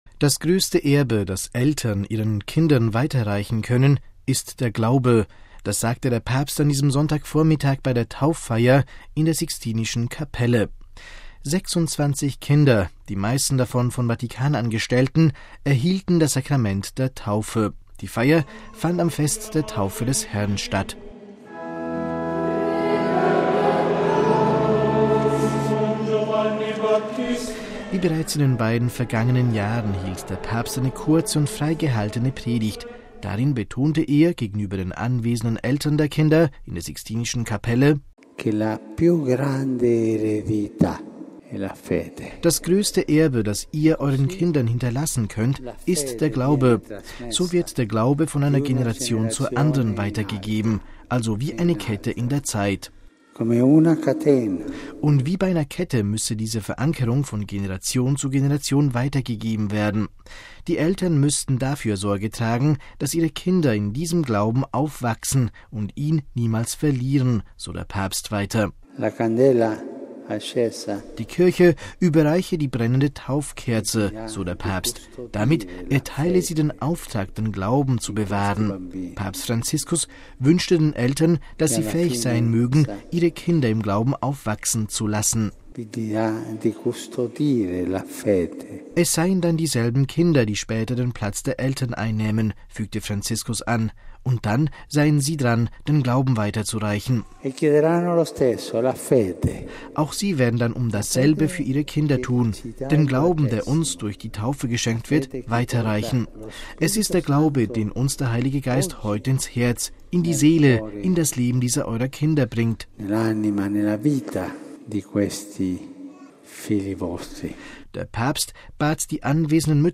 Das größte Erbe, das Eltern ihren Kindern weiter reichen können, ist der Glaube. Das sagte der Papst an diesem Sonntagvormittag bei der Tauffeier in der Sixtinischen Kapelle. 26 Kinder – die meisten davon von Vatikanangestellten – erhielten das Sakrament der Taufe.
Wie bereits in den beiden vergangenen Jahren hielt der Papst eine kurze und frei gehaltene Predigt.